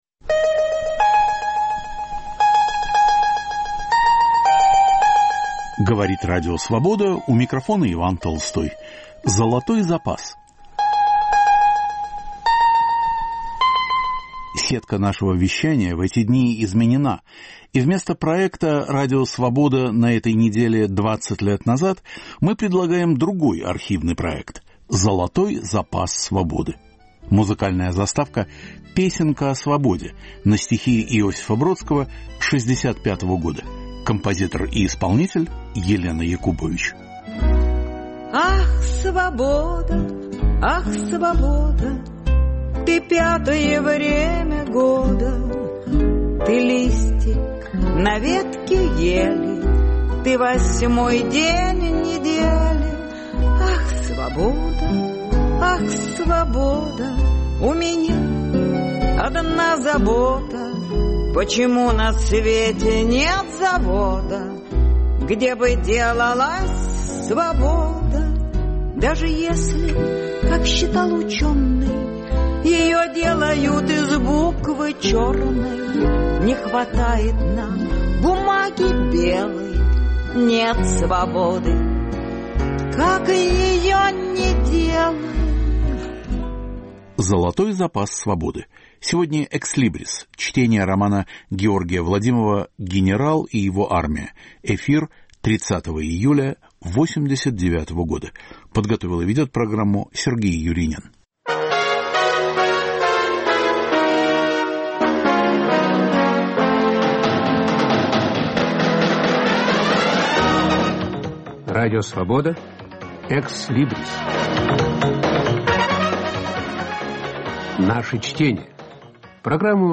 Чтение романа Георгия Владимова "Генерал и его армия". Читает Юлиан Панич в присутствии автора, разъясняющего особенности сюжета.